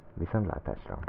Ääntäminen
US : IPA : [ɡʊd.ˈbaɪ]